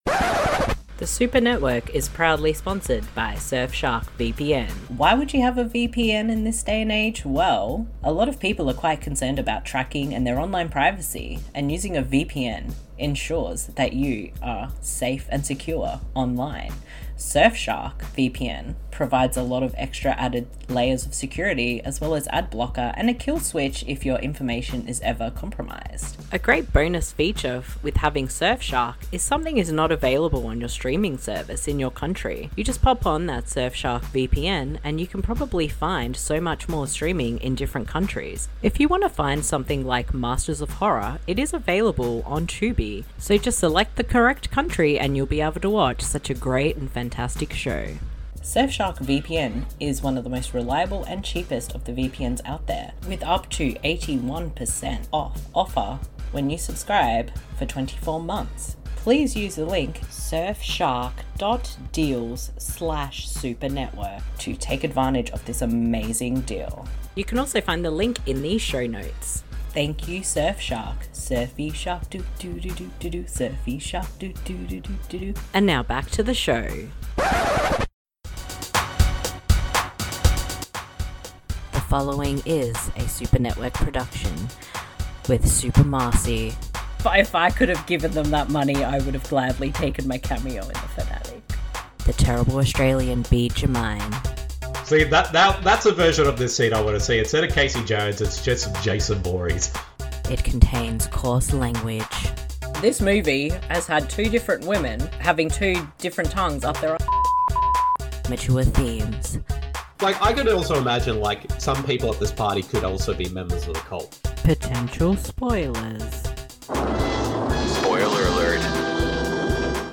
* Did someone say impressions?